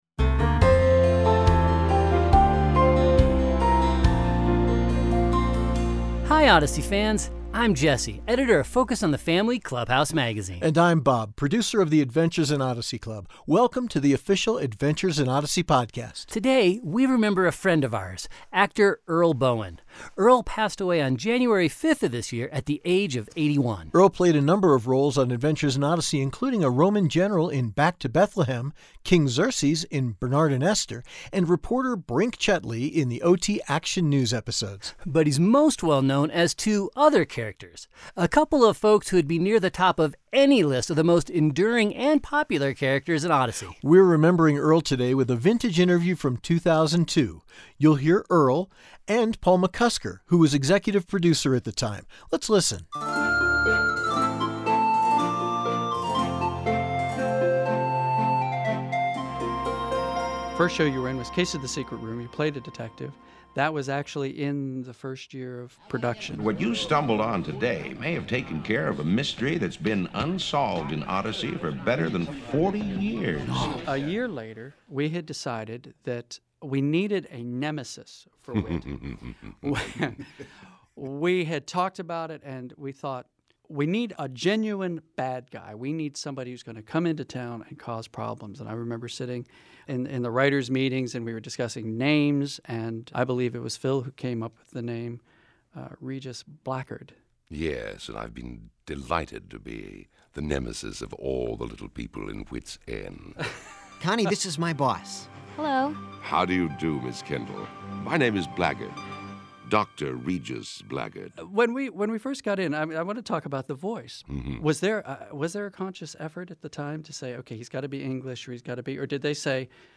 In this vintage interview from 2002, Earl remembers the creation of Odyssey supervillain — the nefarious Dr. Regis Blackgaard — and his twin brother — Shakespearean actor Edwin Blackgaard. Plus, never-before-heard recordings of classic Blackgaard scenes.